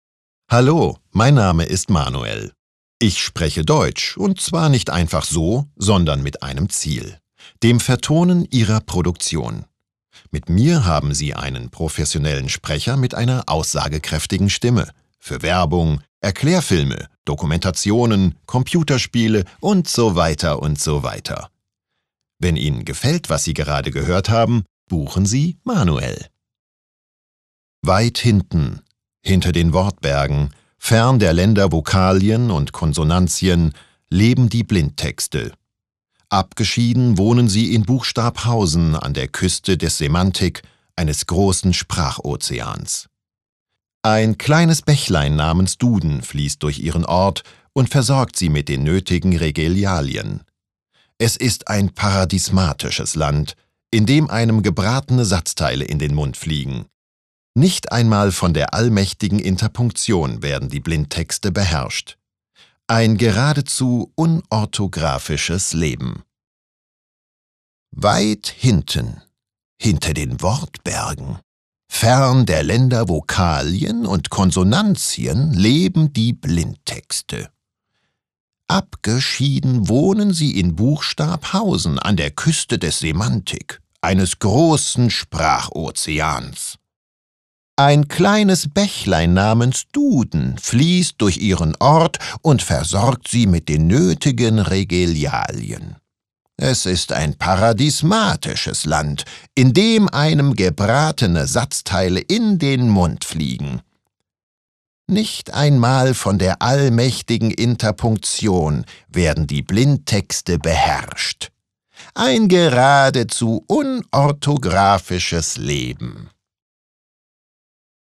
FEINTON | Tonstudio Frankfurt Werbesprecher | Deutsch
Sprecherprofile